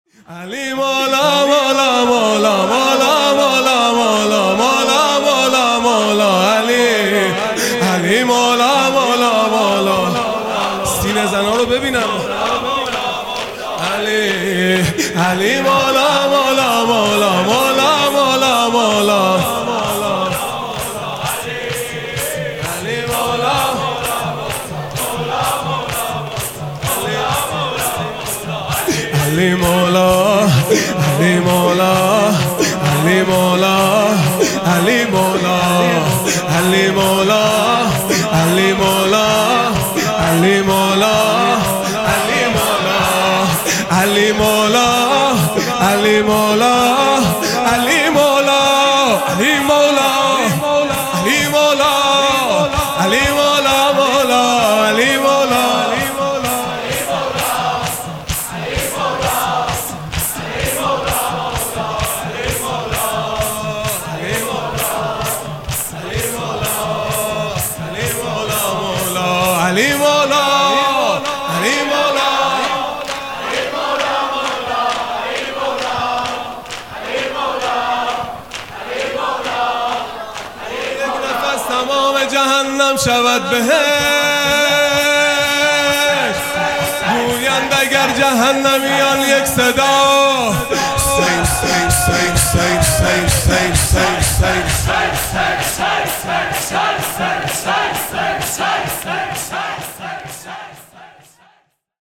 خیمه گاه - هیئت بچه های فاطمه (س) - شور | با یک نفس تمام جهنم شود بهشت | ۲ مرداد ۱۴۰۲
محرم الحرام ۱۴۴5 | شب هفتم